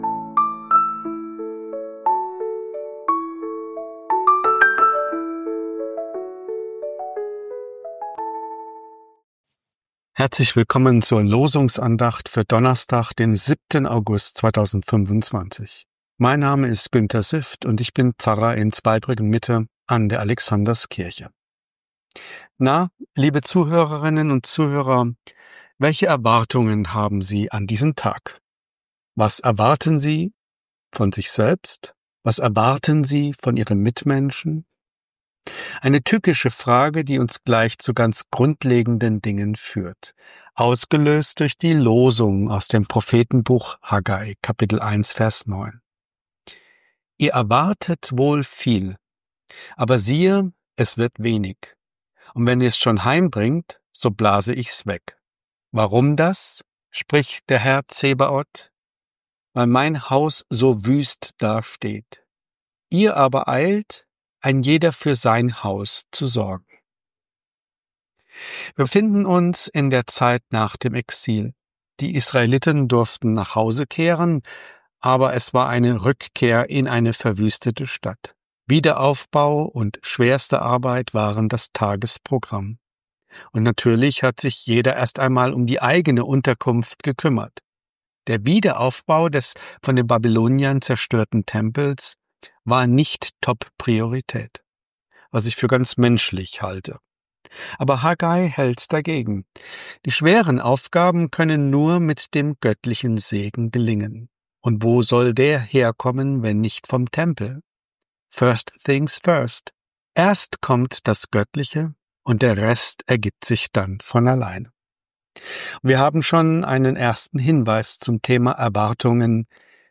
Losungsandacht für Donnerstag, 07.08.2025